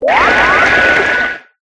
Water5.ogg